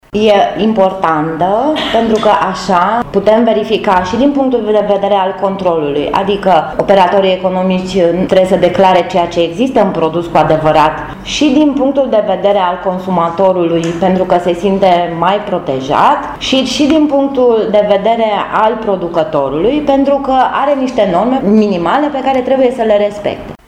La conferința de presă săptămânală a organizației ALDE Brașov, a fost prezentată o inițiativă legislativă, susținută de parlamentarii acestei formațiuni politice, pentru înființarea unei agenții pentru calitatea și marketingul produselor agroalimentare româneşti.